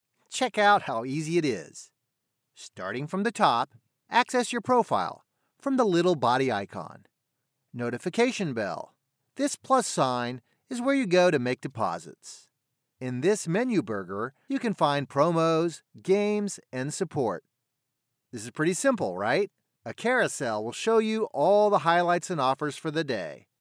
• For voiceovers – a home studio with acoustic treatment, CM25 MkIII condenser microphone, Focusrite Scarlett Si2 interface, Adobe Audition Software.
Tutorial